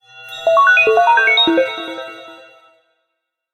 06744 martian code ding
bleep code computer futuristic martian password random robot sound effect free sound royalty free Sound Effects